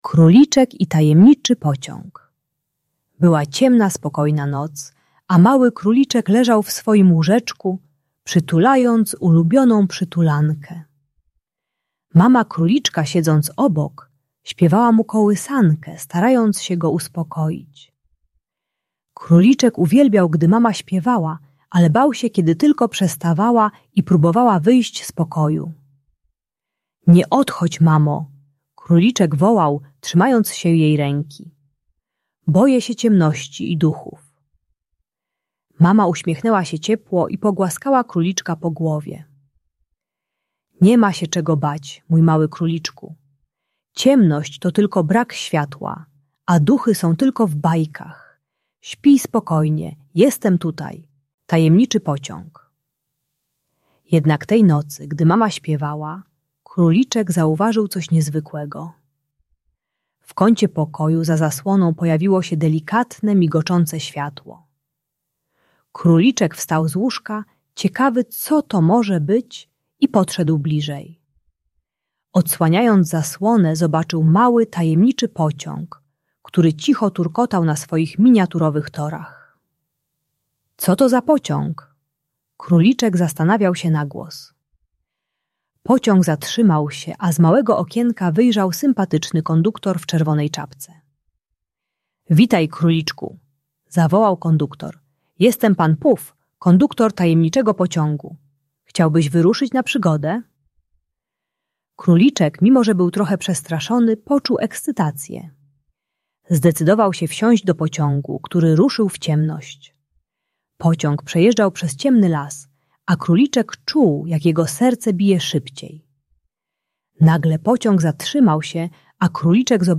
Króliczek i Tajemniczy Pociąg - Lęk wycofanie | Audiobajka